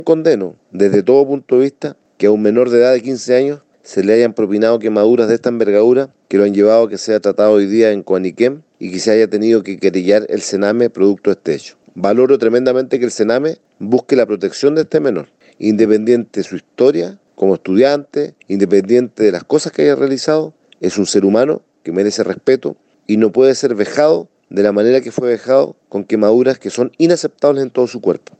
Por su parte, el propio diputado Fidel Espinoza destacó la acciones legal presentada por el Sename, sosteniendo que este caso no puede quedar impune.